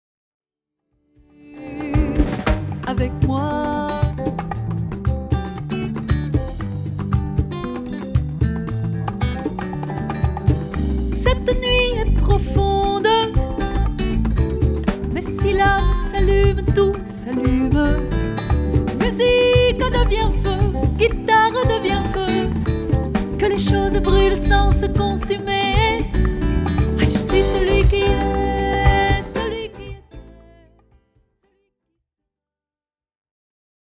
Du jazz au folk et au fado, en passant par le flamenco